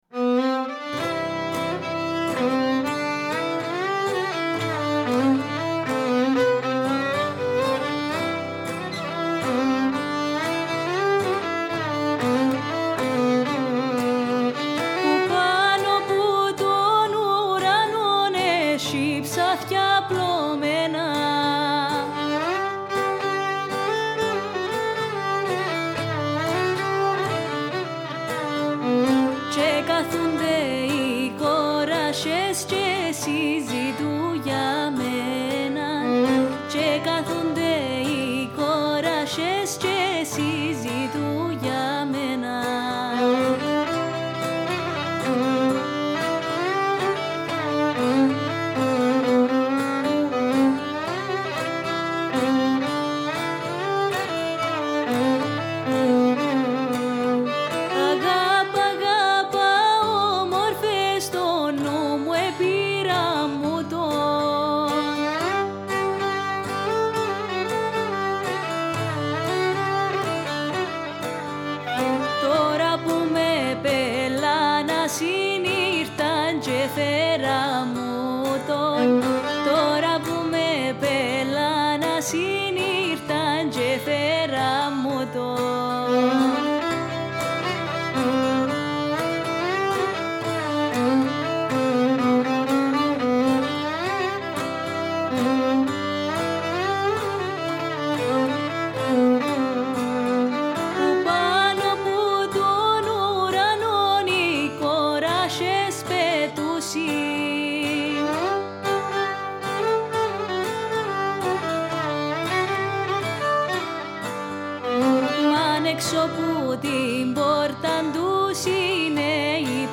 Η κυπριακή παραδοσιακή μουσική